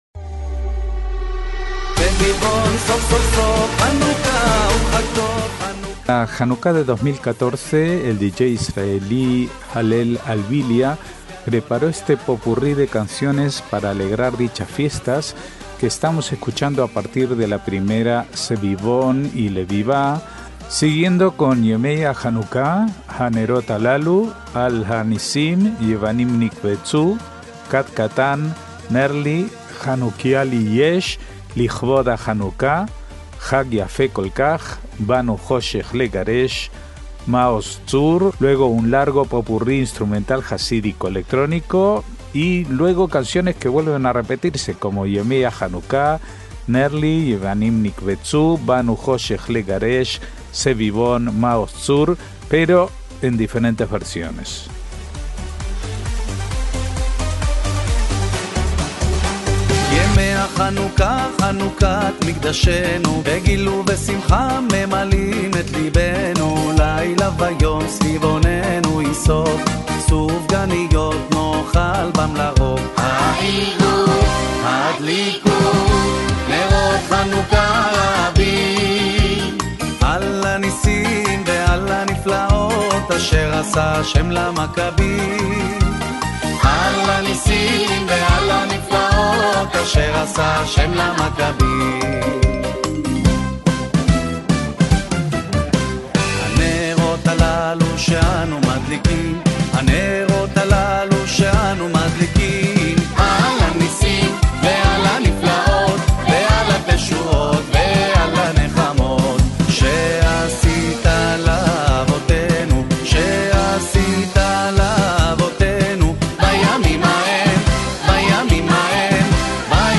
MÚSICA ISRAELÍ
un popurrí instrumental jasídico electrónico